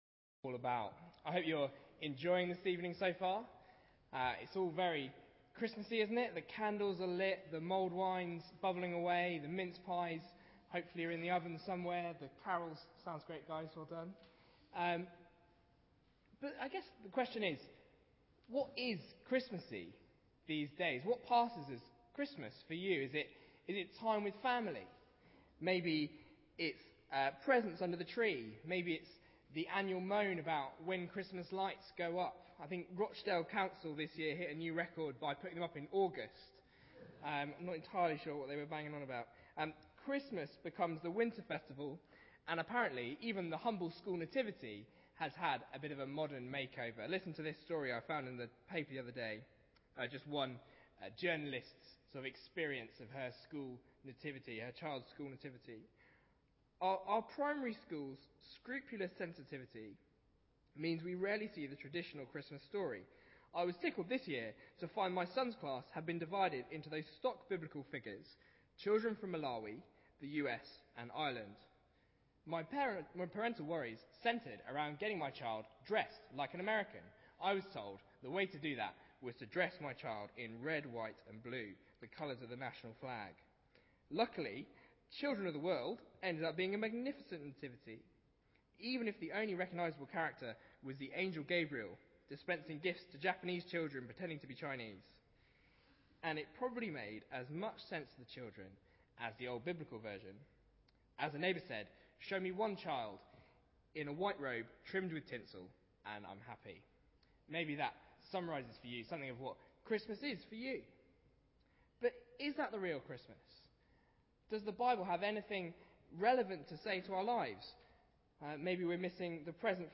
Informal carol service